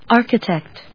音節ar・chi・tect 発音記号・読み方
/άɚkətèkt(米国英語), άːkətèkt(英国英語)/